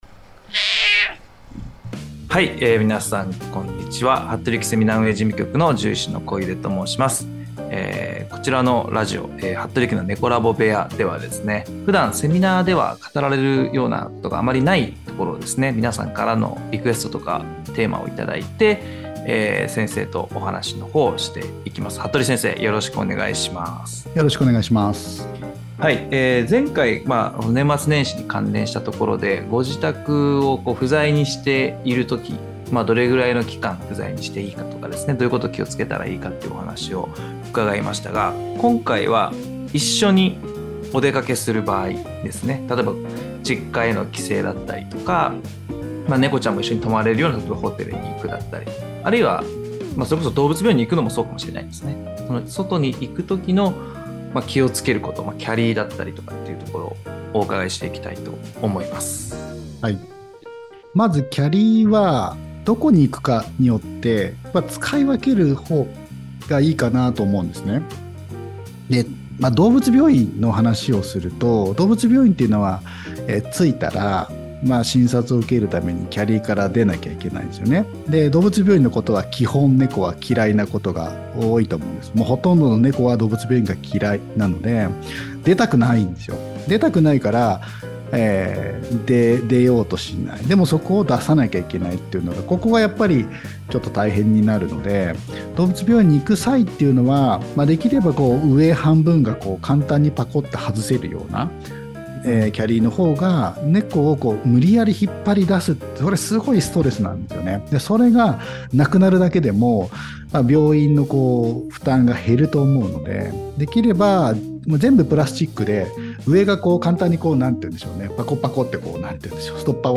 ▼オープニング、エンディング鳴き声：